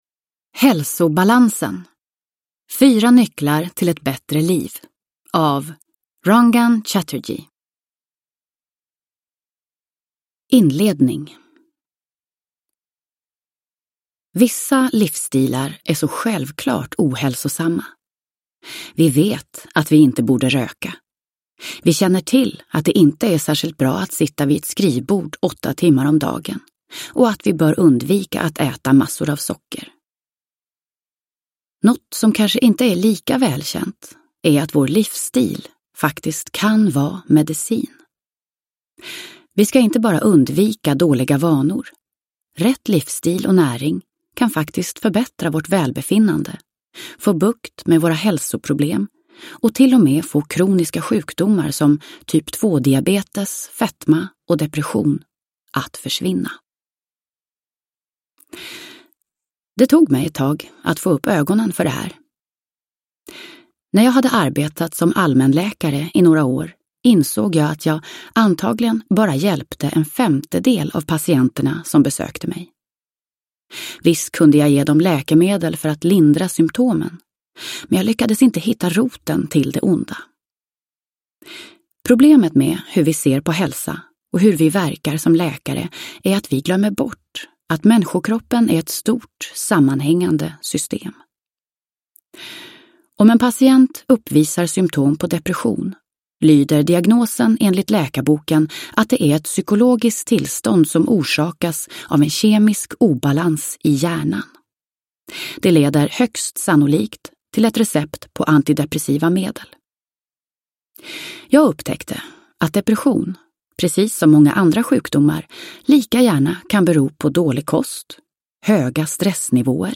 Hälsobalansen : 4 nycklar till ett bättre liv - hur du vilar, äter, tränar och sover dig friskare – Ljudbok – Laddas ner